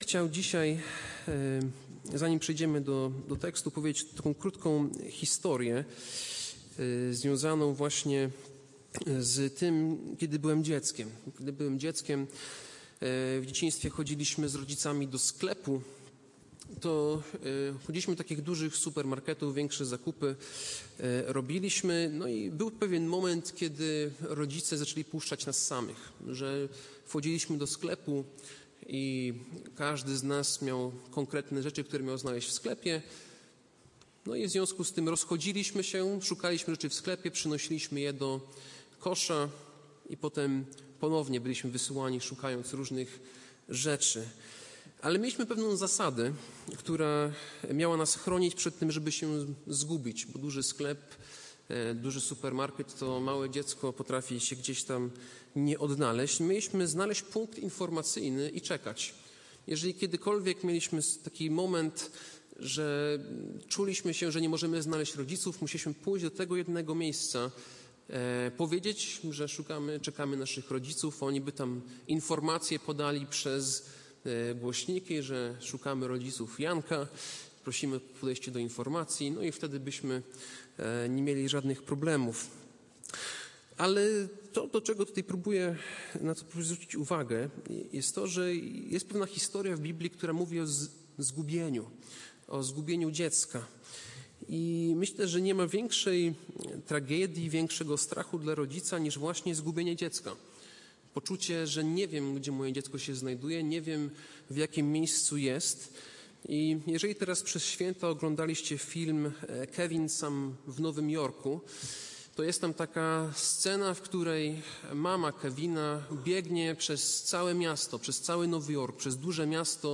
Passage: Ewangelia św. Łukasza 2, 39-52 Kazanie